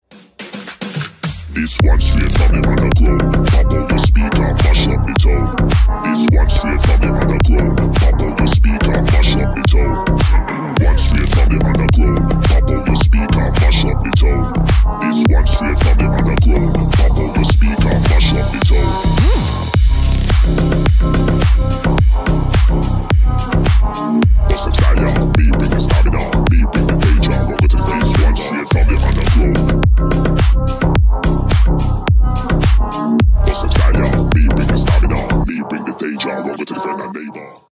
# клубные
# рэп